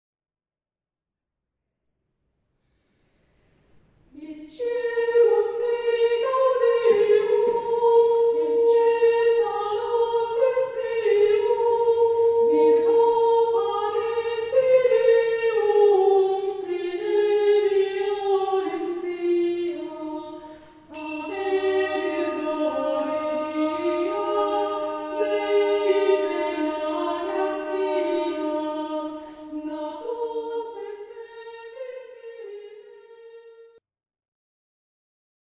monodia e polifonia medievali
Ecce mundi gaudium Firenze, Pluteus 29,1, rondellus, XIII sec., f. 470r